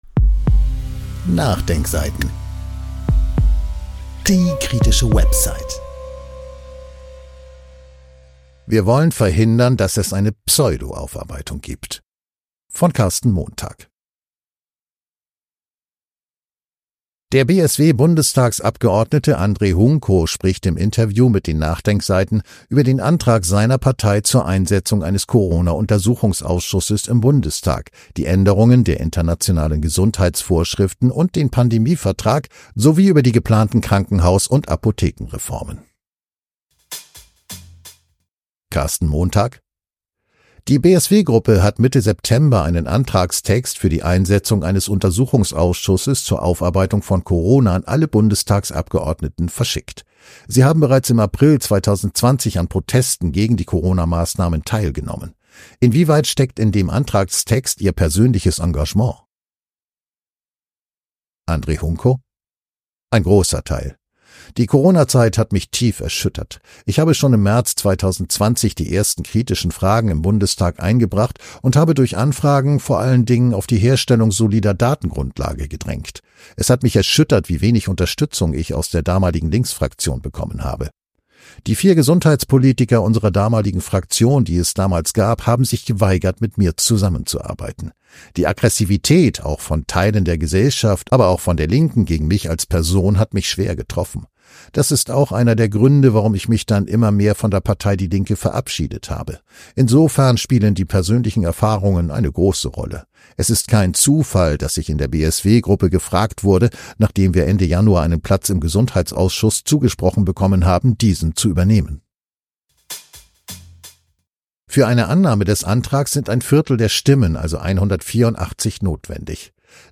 Der BSW-Bundestagsabgeordnete Andrej Hunko spricht im Interview mit den NachDenkSeiten über den Antrag seiner Partei zur Einsetzung eines Corona-Untersuchungsausschusses im Bundestag, die Änderungen der Internationalen Gesundheitsvorschriften und den Pandemievertrag sowie über die geplanten Krankenhaus- und Apothekenreformen.